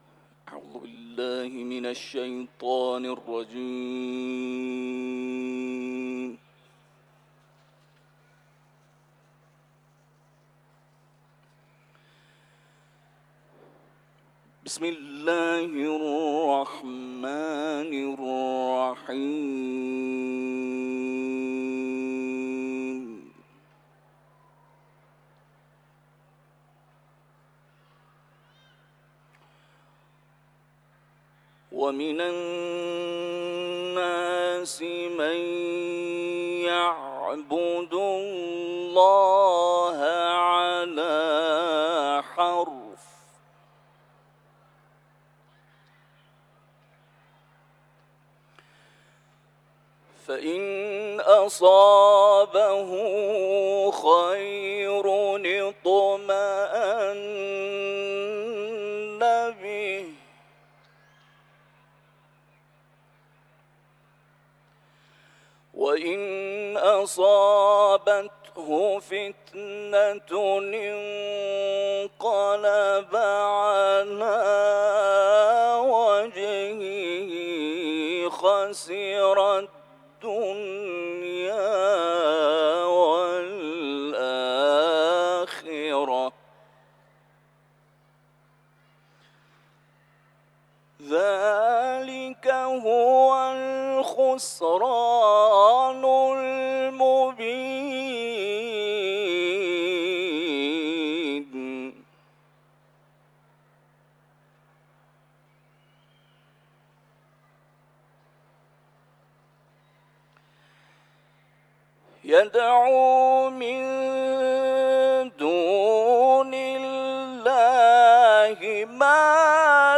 صوت | تلاوت